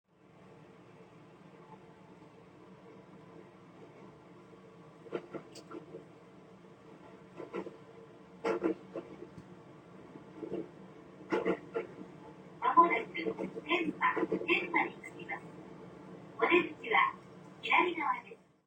電車内の音」を録音したものをオーディオシステムで大音量で再生して、疑似的に電車内の環境を作ります。
バイノーラル録音マイクを耳に取り付け、その上からヘッドホンを耳に装着してノイズキャンセリングをオンにします。
この状態でオーディオスピーカーから鳴っている駅の環境音がどのようにキャンセルされるかを録音した。
AirPods Max ノイキャンあり
どちらも確実にノイズは低減されています
ただ、AirPods Maxは実際でもかなり安定しています